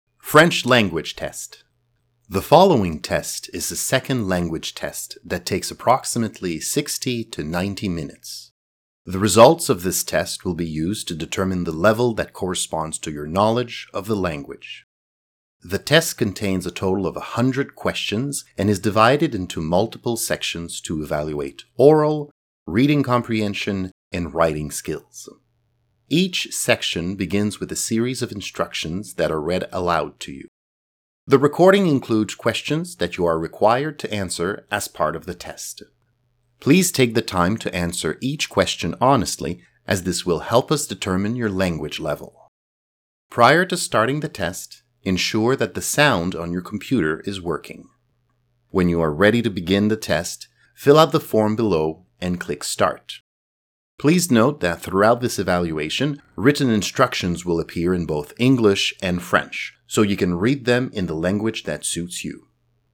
Each section begins with a series of instructions that are read aloud to you.